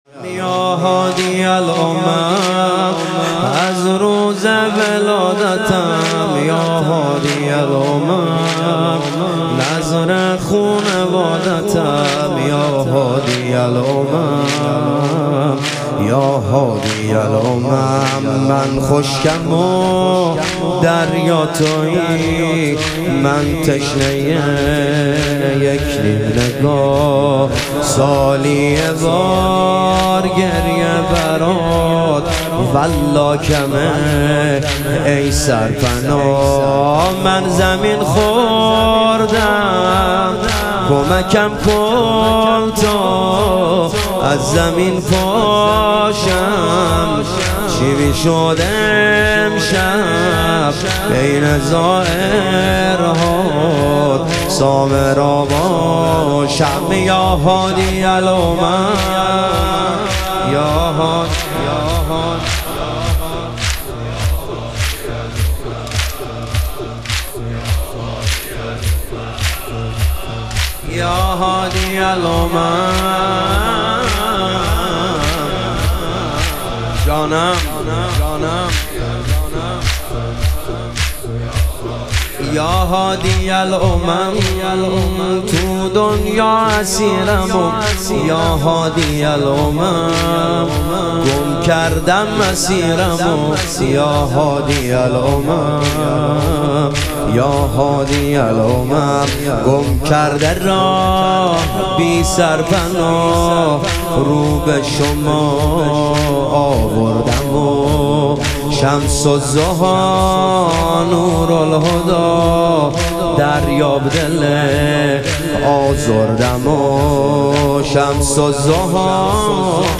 شهادت امام هادی علیه السلام - تک